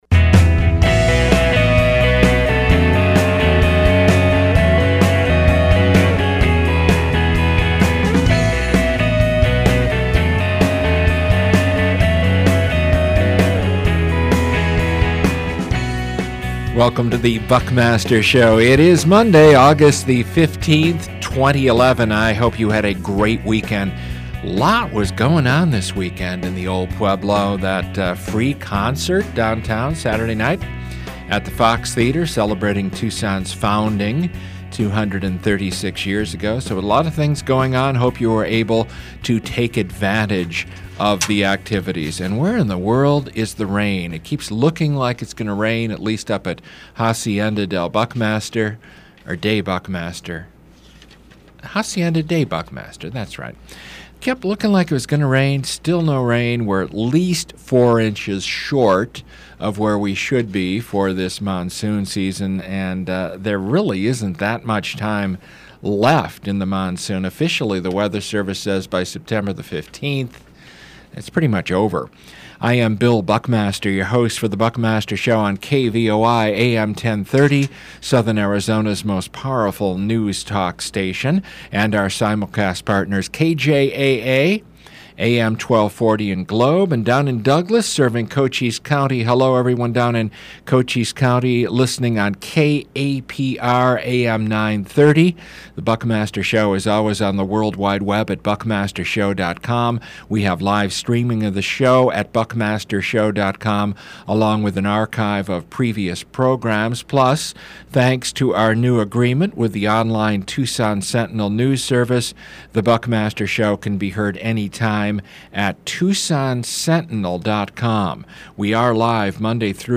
Following that is a newsmaker interview with Arizona Representative Terri Proud.